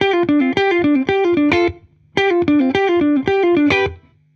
Index of /musicradar/dusty-funk-samples/Guitar/110bpm
DF_70sStrat_110-D.wav